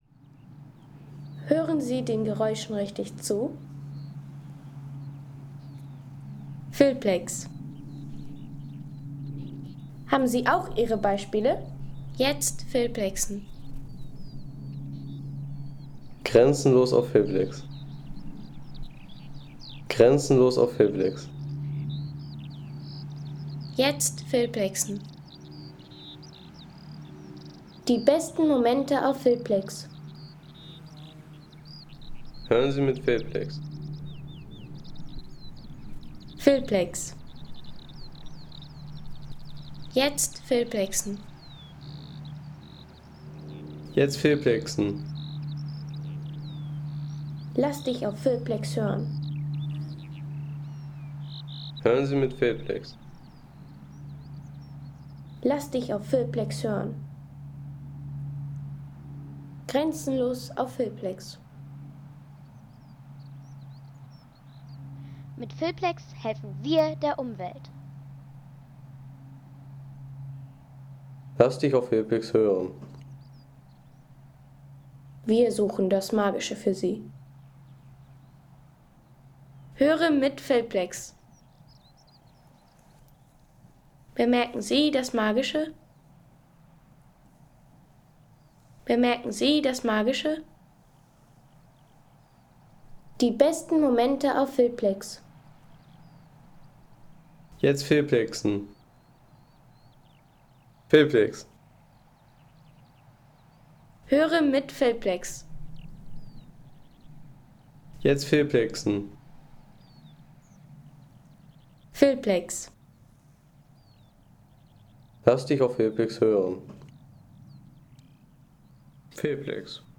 Wiesenlandschaft in der Rhön mit Segelflugzeugen und Wiesenpiepern.